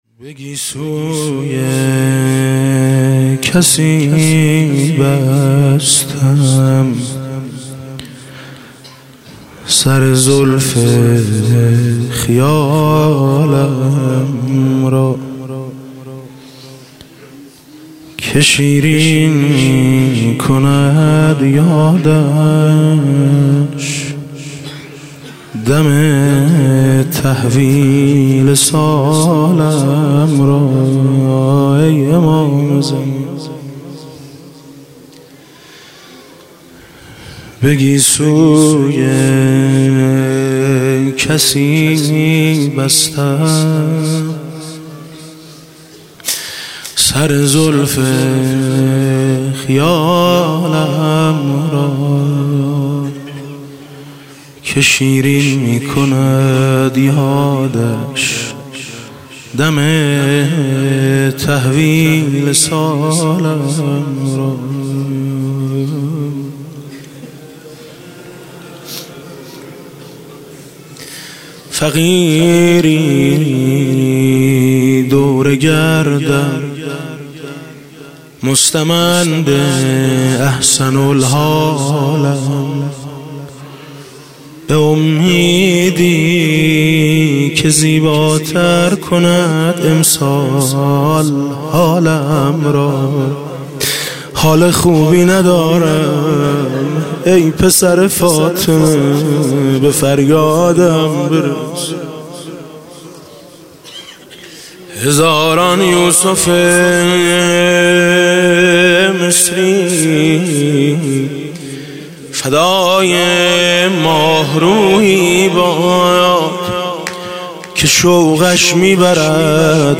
21 فروردین 97 - هیئت میثاق با شهدا - مناجات - به وصلش می رسم یا نه
مناجات حاج میثم مطیعی